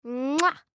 Звуки воздушного поцелуя
Шепот воздушного поцелуя от девушки